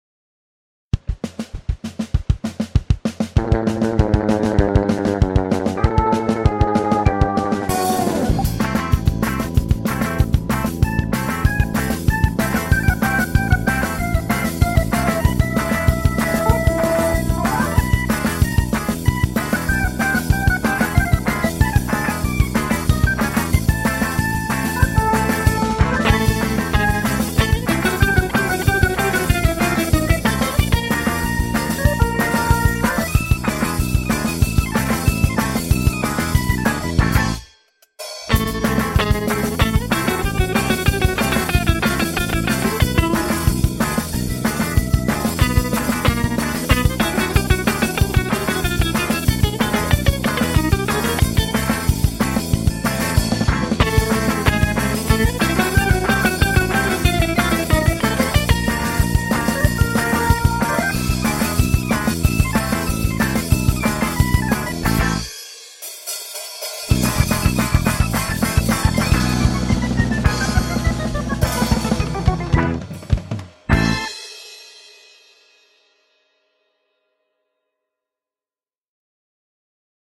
• Категория: Детские песни
🎶 Детские песни / Песни из мультфильмов